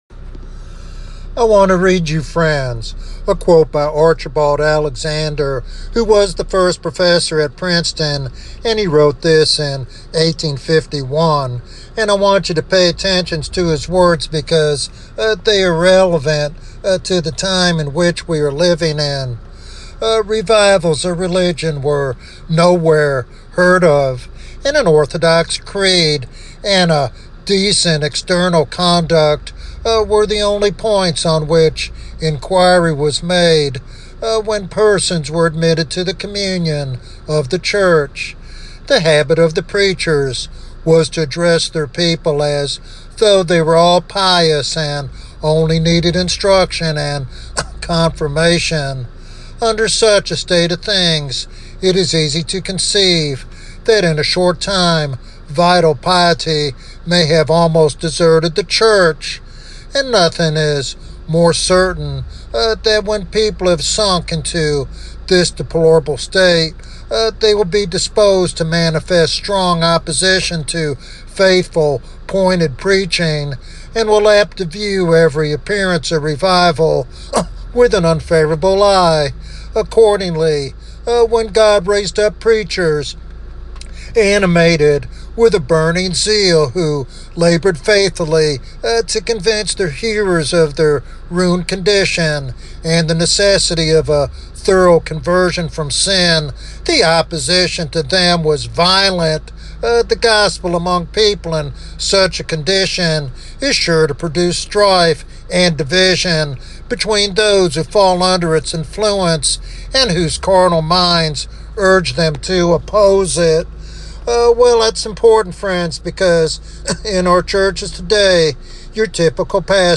This sermon challenges believers and church leaders to rekindle a fervent faith that prioritizes eternal realities over earthly comforts.